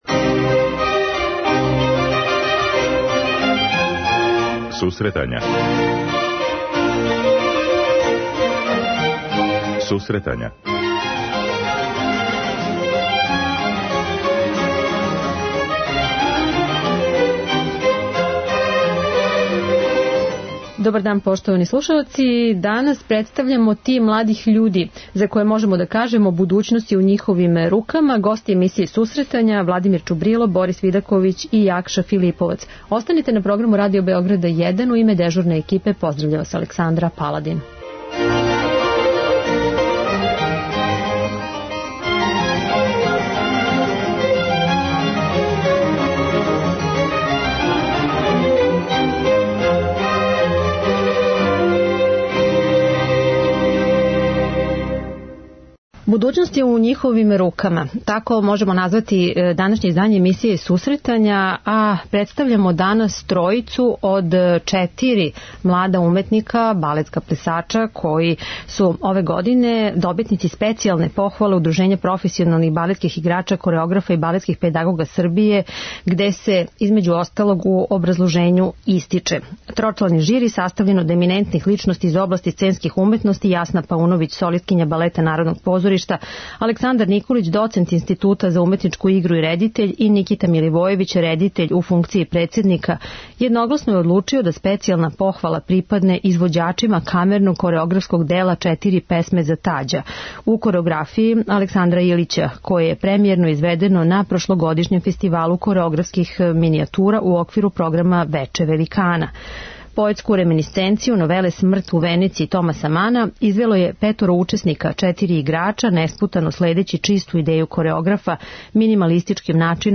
Ови млади уметници су и гости данашњих „Сусретања“.